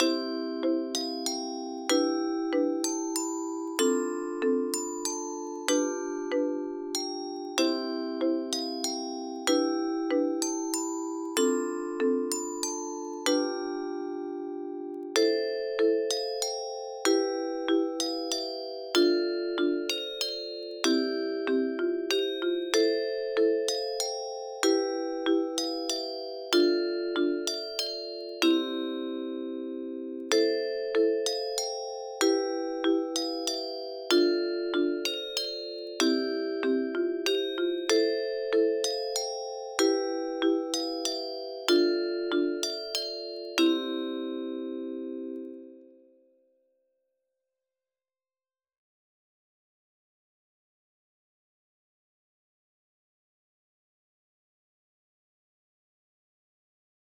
Cançó tradicional catalana